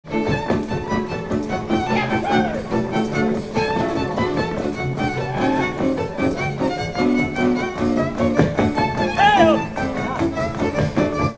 Műfaj Ugrós
Részl.műfaj Dus
Hangszer Zenekar
Helység Szany
Megye Sopron